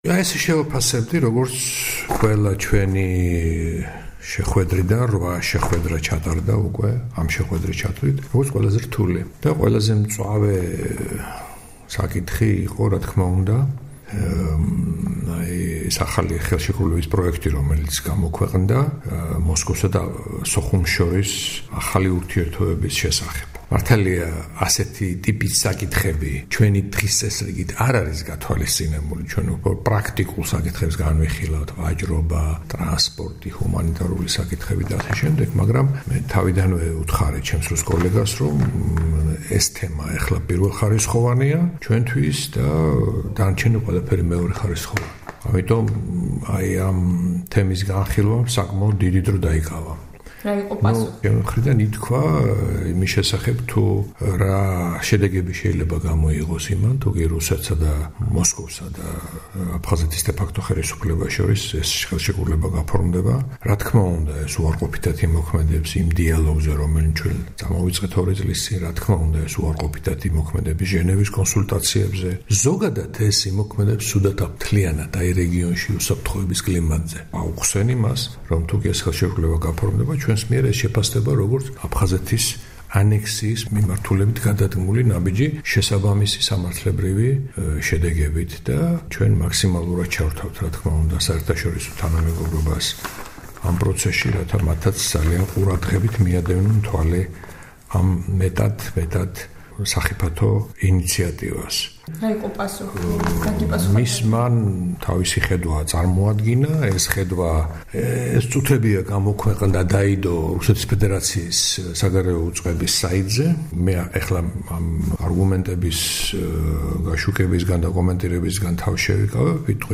საუბარი ზურაბ აბაშიძესთან